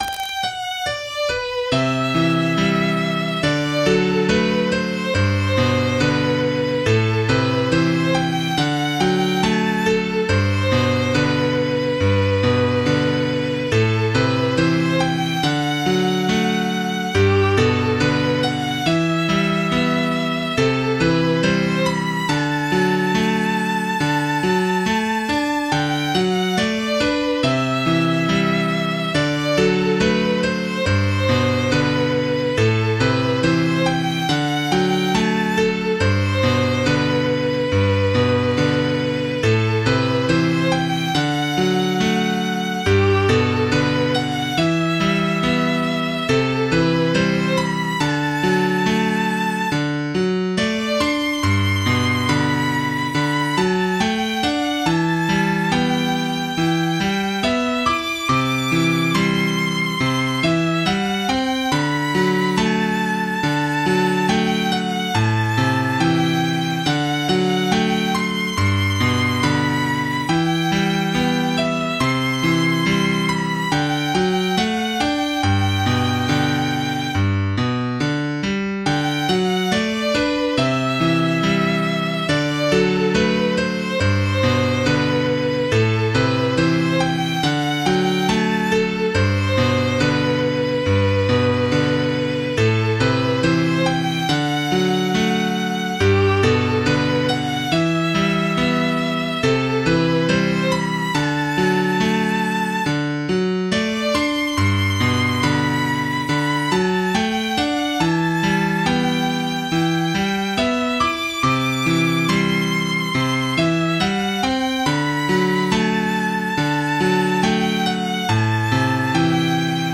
Karaoke Tracks